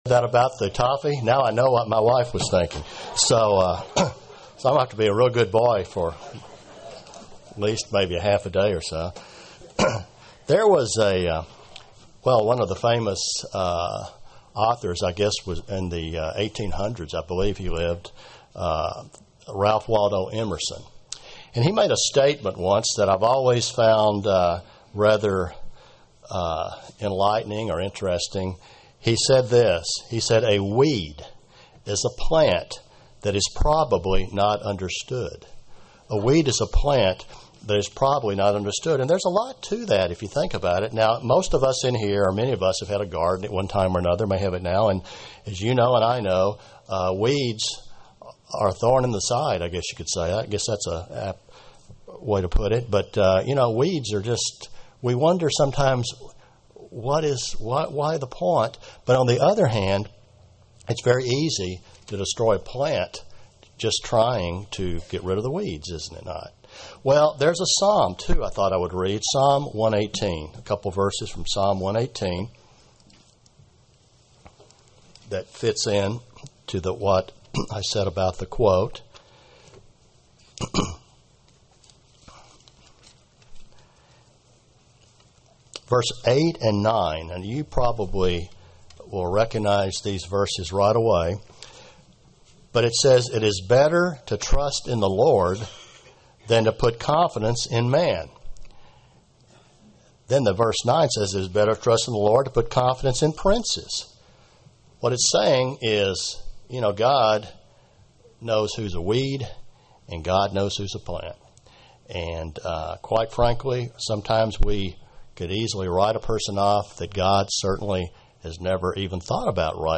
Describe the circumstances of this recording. Given in Tampa, FL